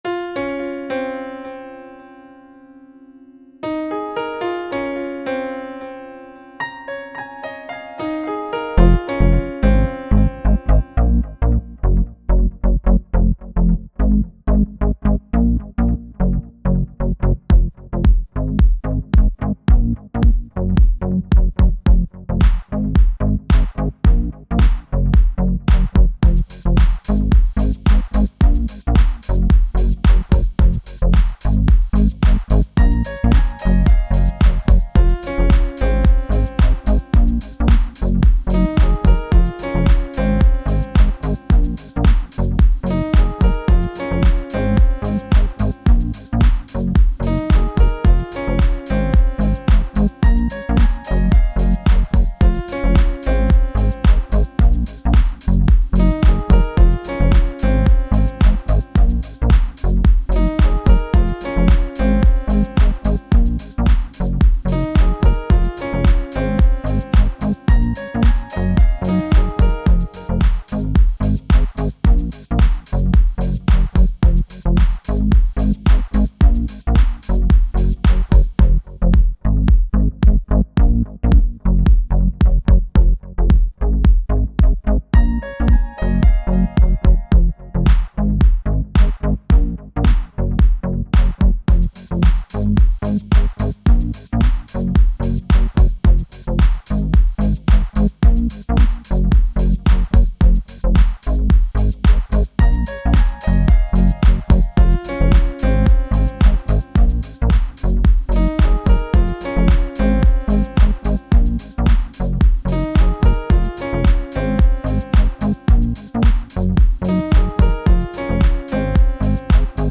royalty free music
02:54 Electronica 4.1 MB